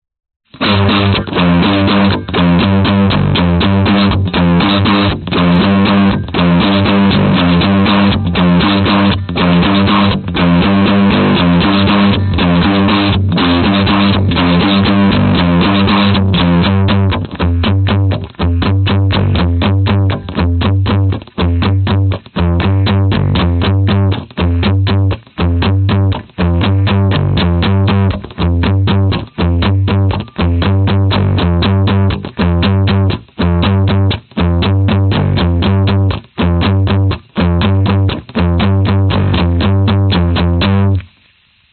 道歉吉他落款C
标签： 吉他 器乐 循环 摇滚 C_minor 电动
声道立体声